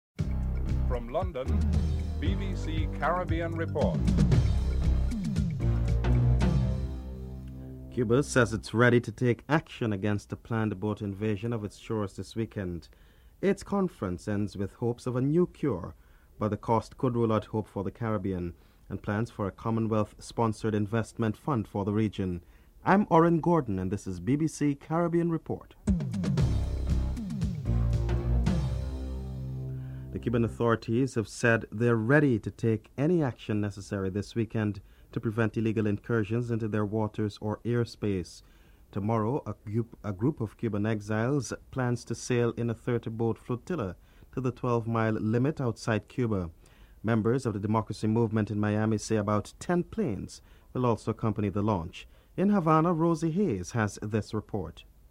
The British Broadcasting Corporation
1. Headlines (00:00-00:28)